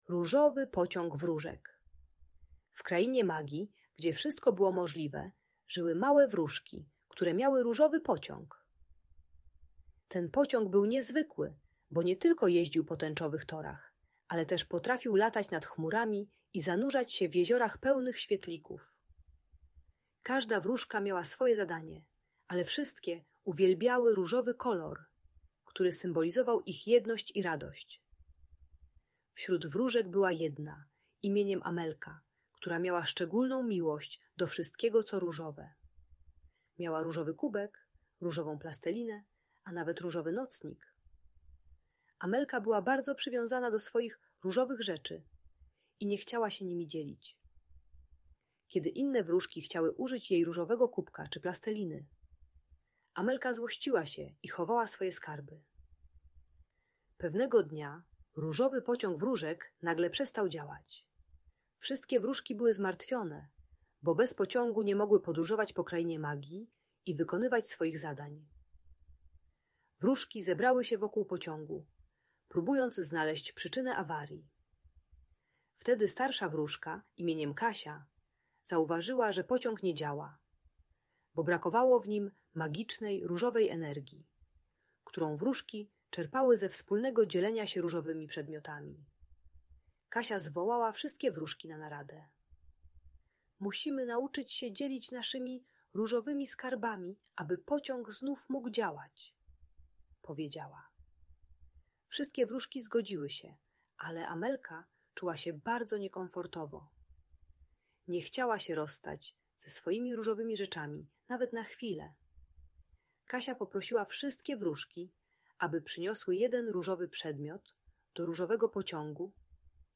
Różowy Pociąg Wróżek - Niepokojące zachowania | Audiobajka